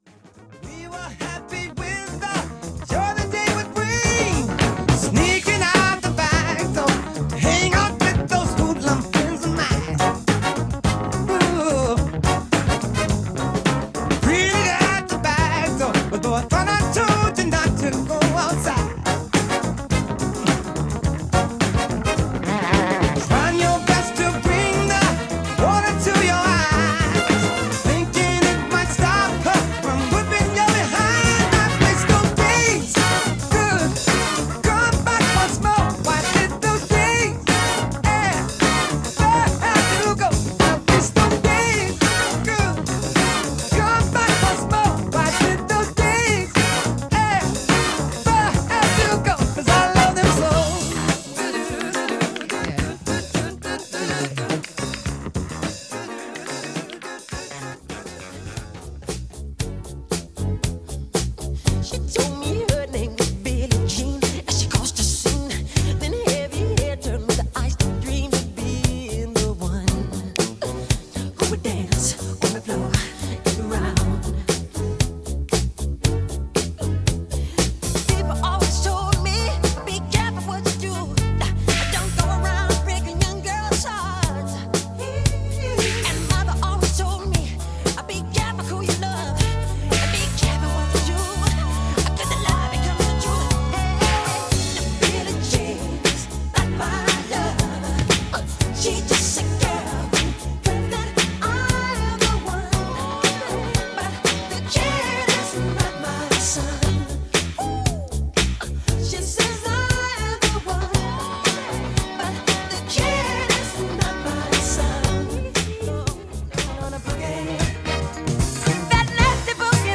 Dance Classics